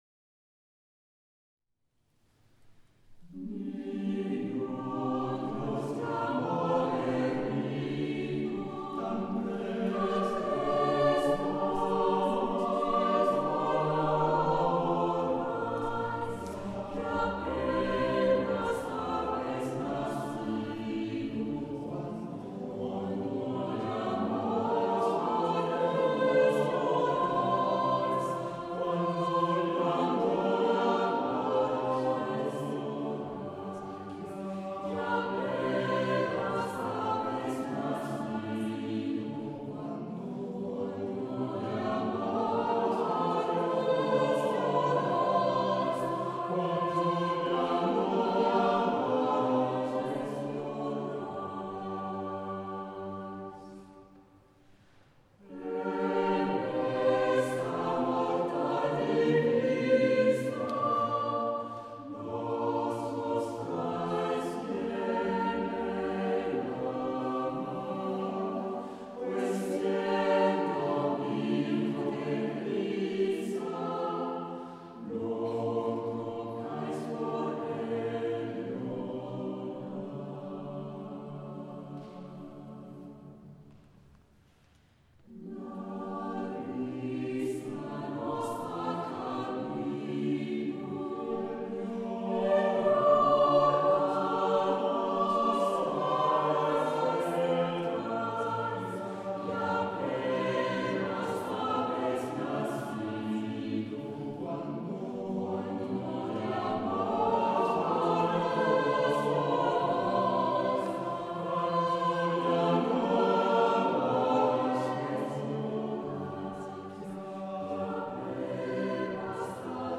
Noëls des XIVe au XXIeme siècle